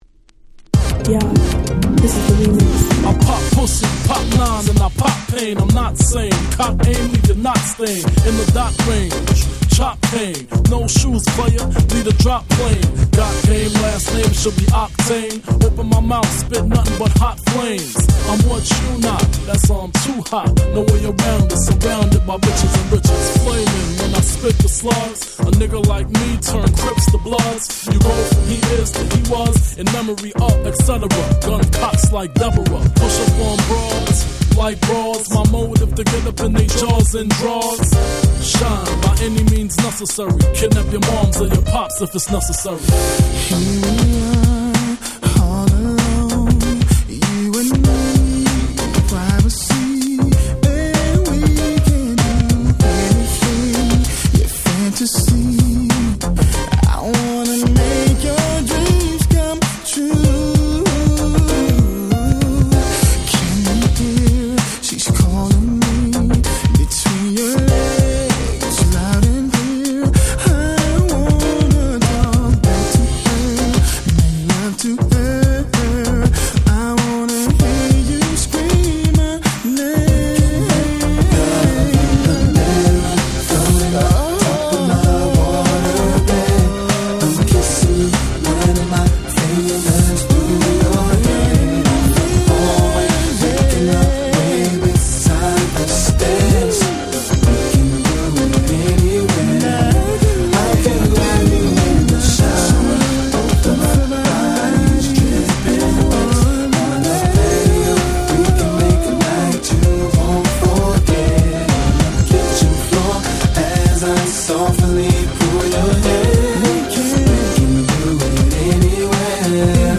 【Media】Vinyl 12'' Single
98’ Super Nice R&B / Hip Hop Soul !!
その上美メロで踊れると来れば言う事無し！！